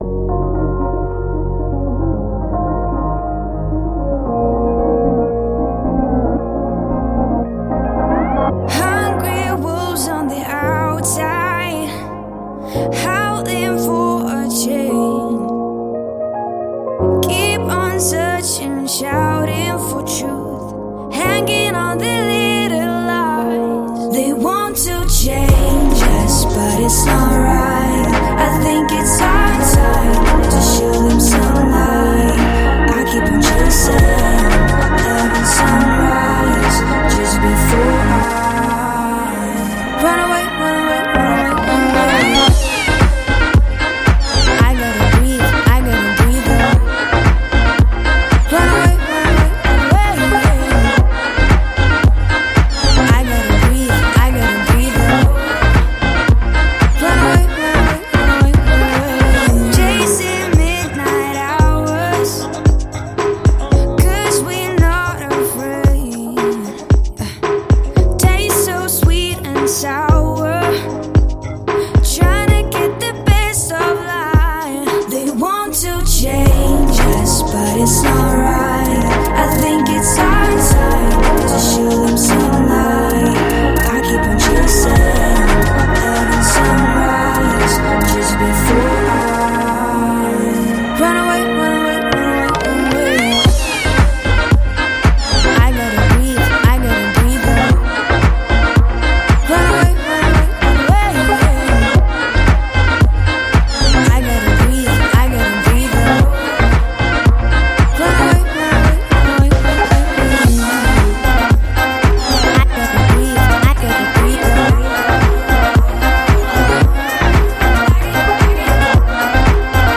• Жанр: Зарубежные песни